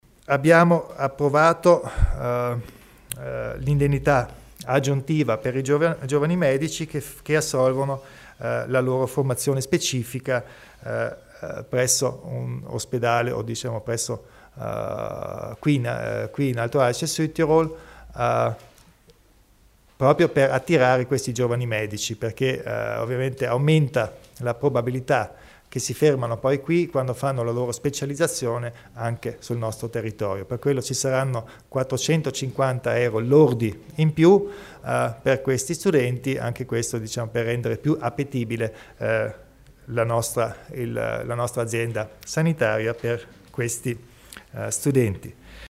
Il Presidente Kompatscher spiega le novità per la formazione sanitaria dei giovani medici